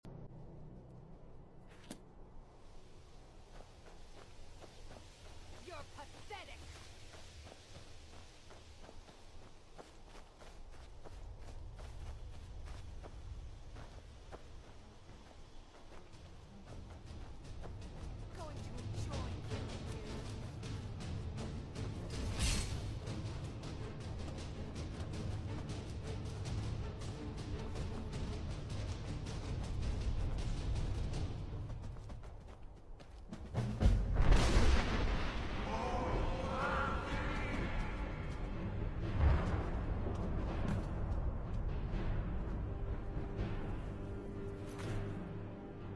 The Greybeards Call in Skyrim from my old playthrough that I shared on my old account.